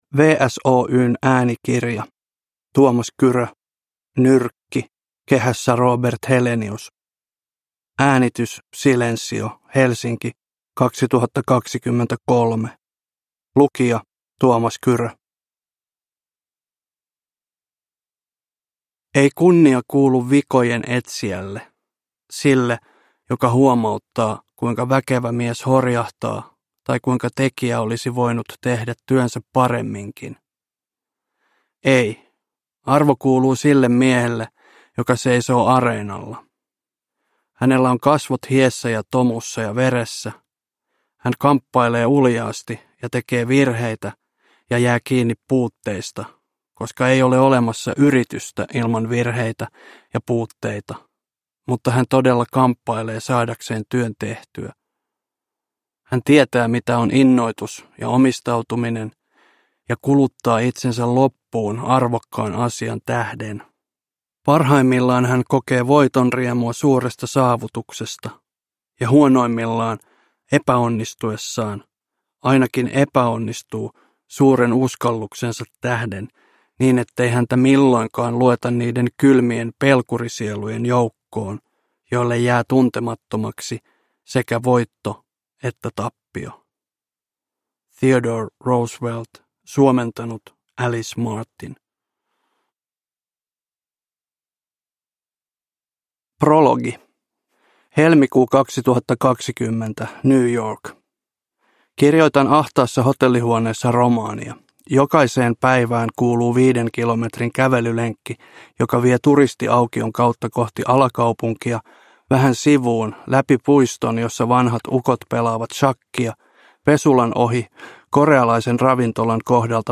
Uppläsare: Tuomas Kyrö